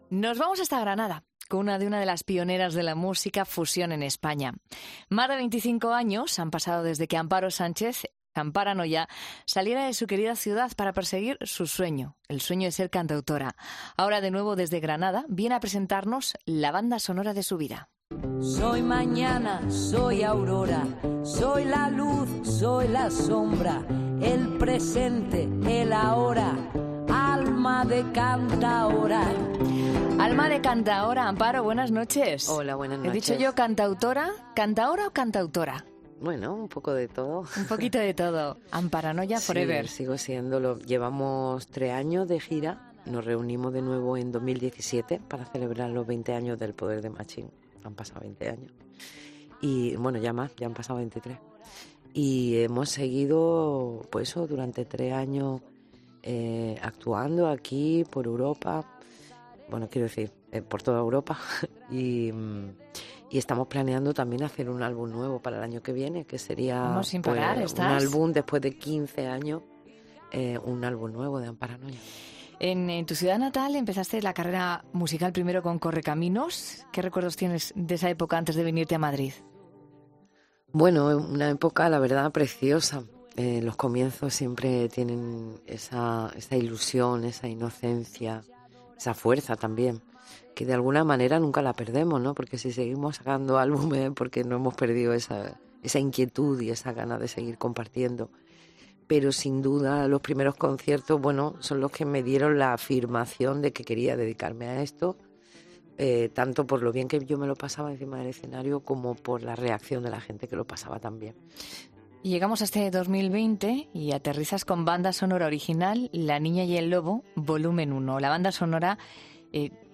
La cantante andaluza se sincera ante los micrófonos de la Cadena COPE